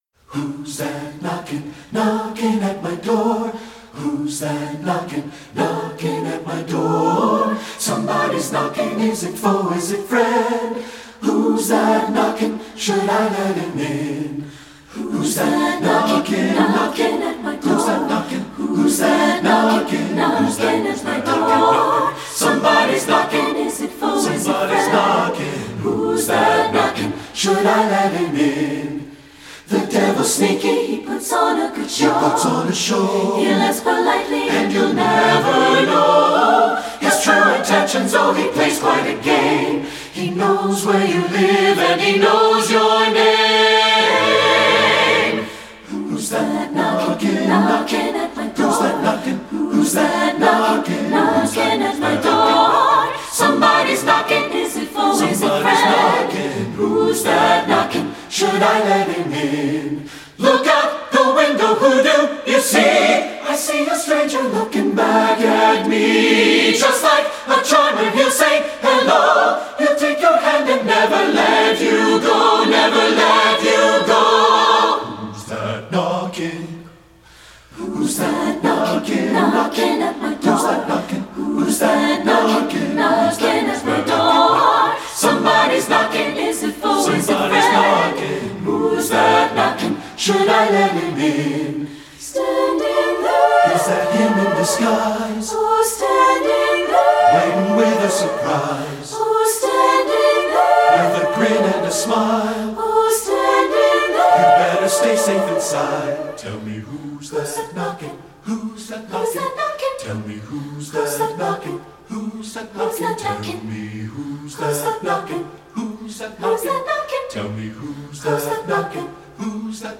secular choral
- SATB a cappella, sample